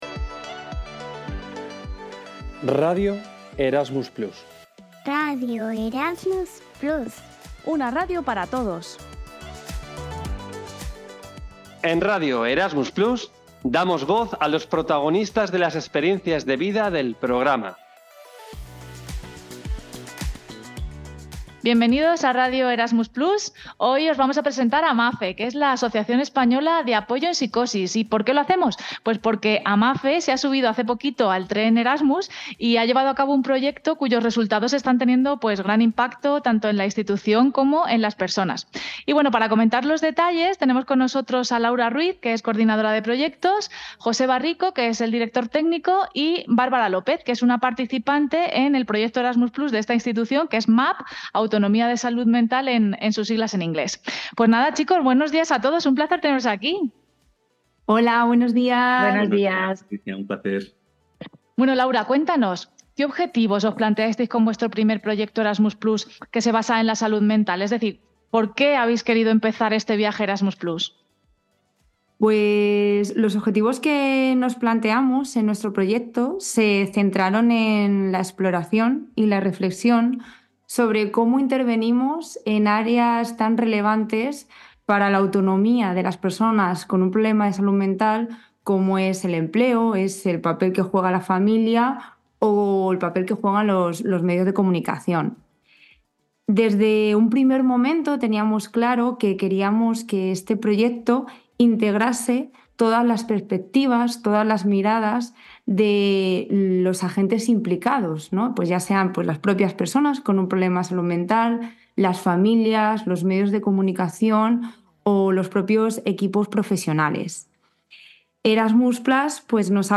audioentrevista_amafe.mp3